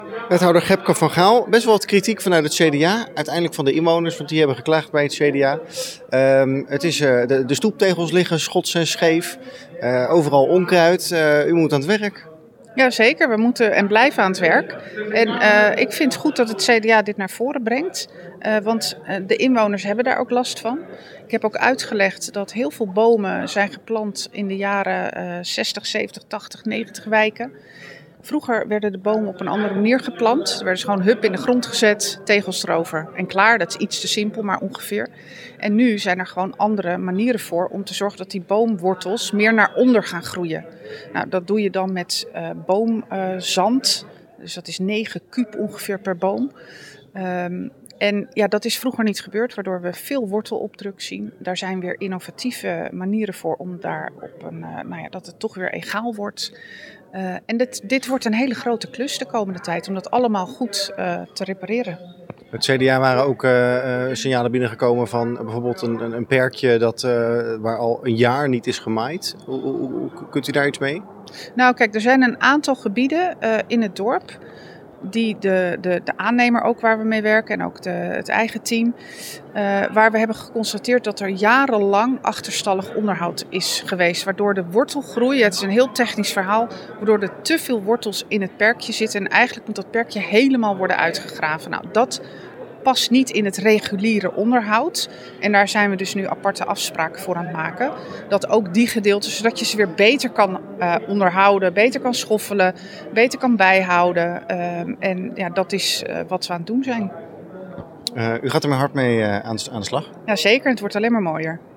Wethouder Gebke van Gaal heeft maandagavond tijdens de raadsvergadering erkend dat het groenonderhoud in Leiderdorp structurele problemen kent.
Wethouder Gebke van Gaal over het groenonderhoud.